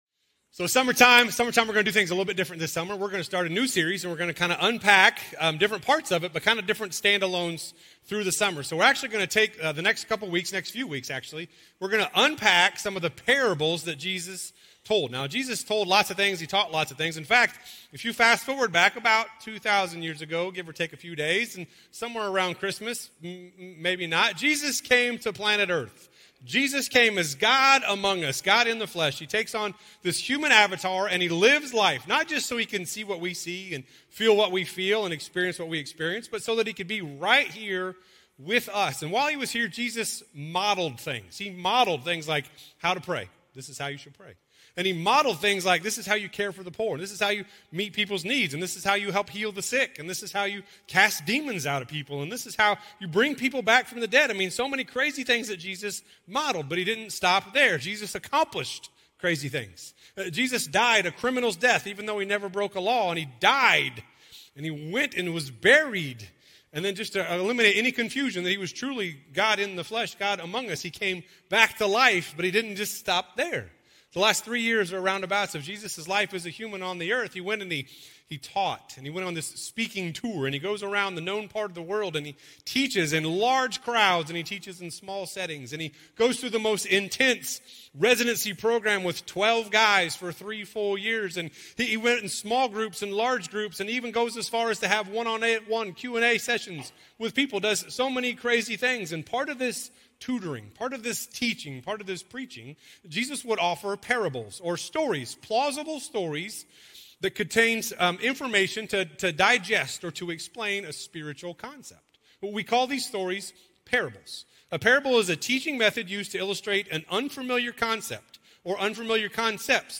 Crossroads Community Church - Audio Sermons